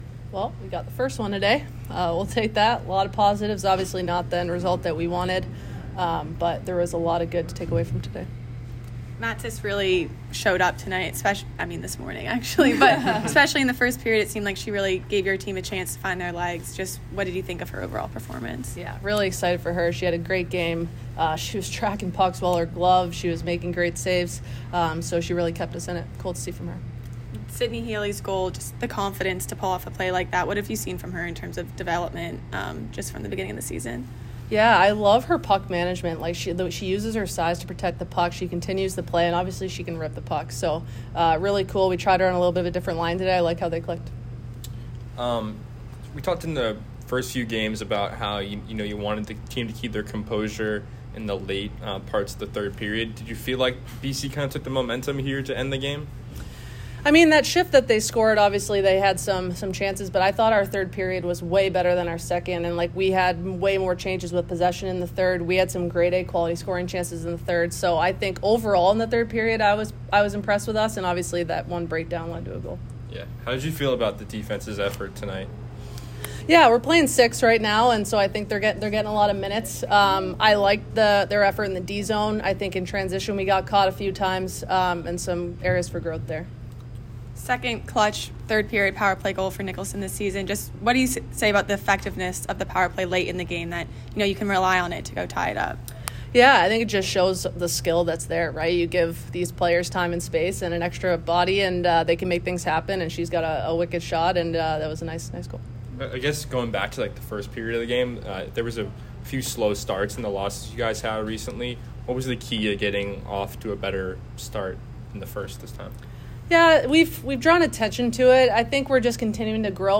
Women's Ice Hockey / Boston College Postgame Interview (11-17-23) - Boston University Athletics